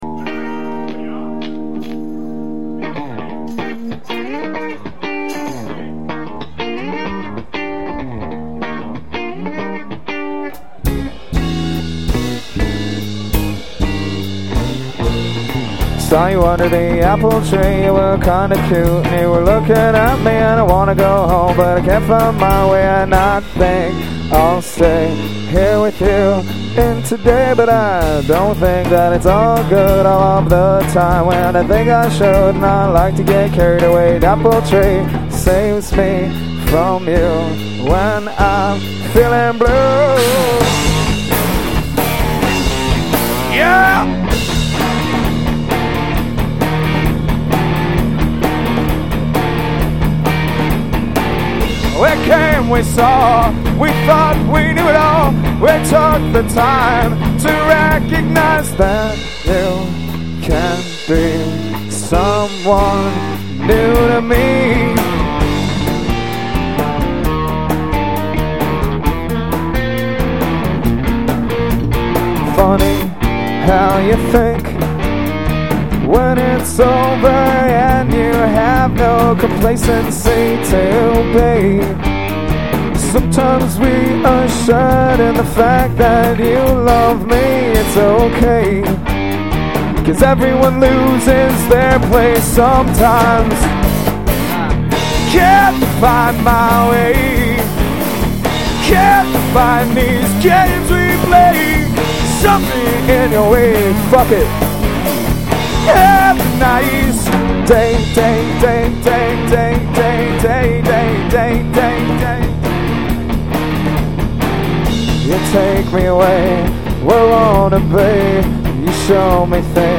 These songs are taken from a live show in Whitehorse.